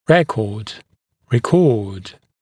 [‘rekɔːd] гл. [rɪ’kɔːd][‘рэко:д] гл. [ри’ко:д]запись (напр. в истории болезни), учетная запись; записывать, делать запись, регистрировать